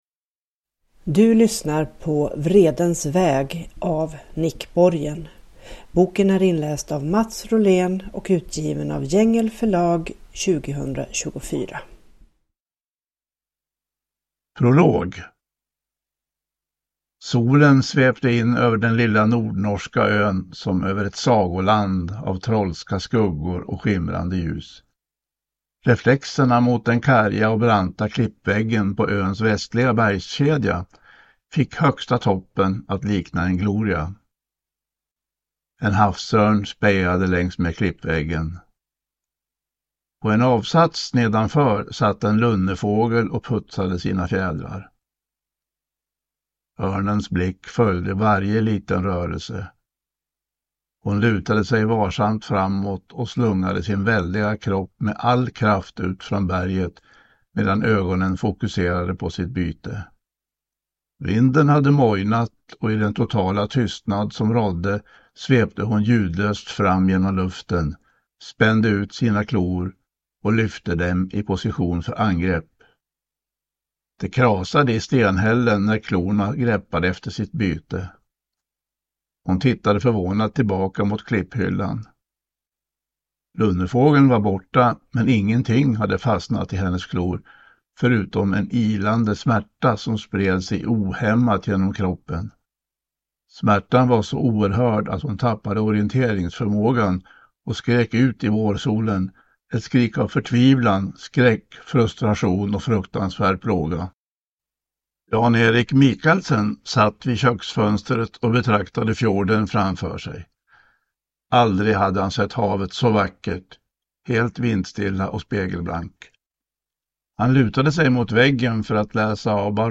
Vredens väg (ljudbok) av Nick Borgen